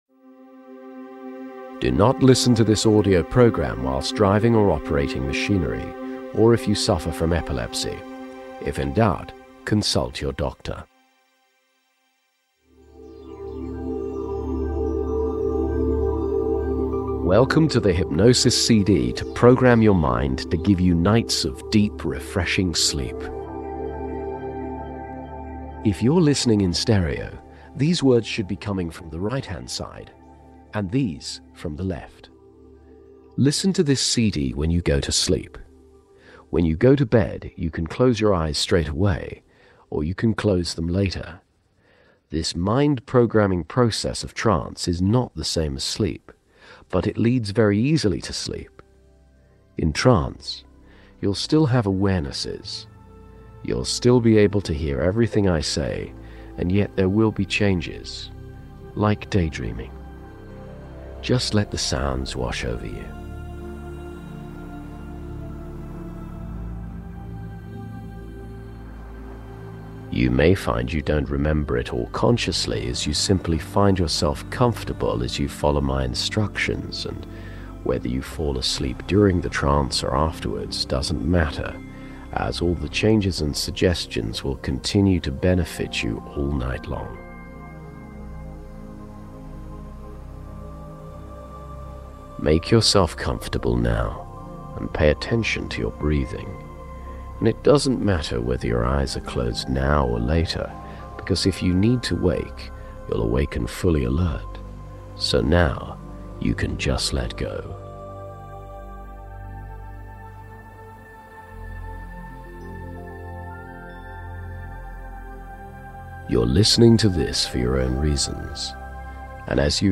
There is a book "I can make you sleep" by Paul McKenna (amazon). The book includes an excellent sleep audio meditation, if you have the CD, but prefer the .mp3, here it is!
SleepMedPaulMcKenna.mp3